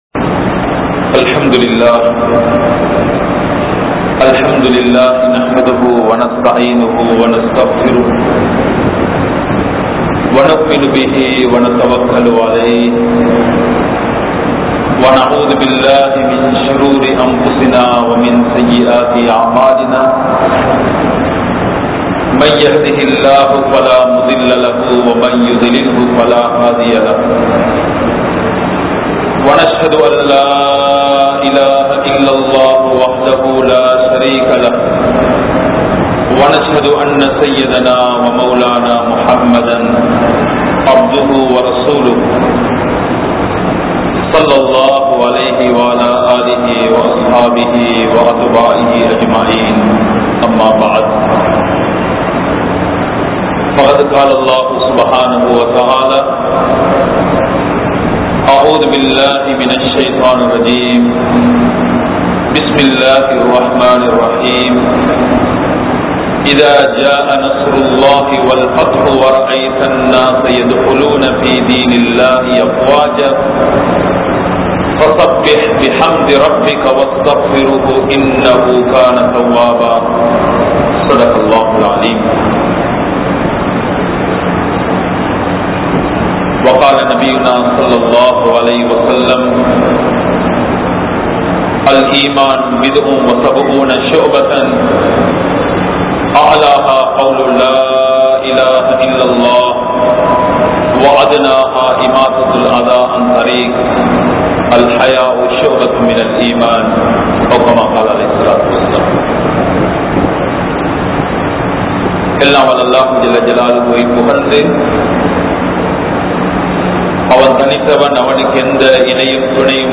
Porumaien Mudivu Vettri | Audio Bayans | All Ceylon Muslim Youth Community | Addalaichenai